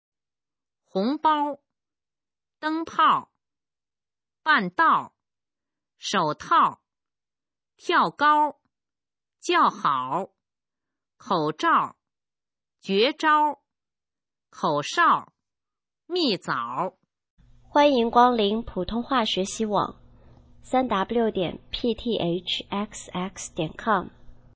普通话水平测试用儿化词语表示范读音第19部分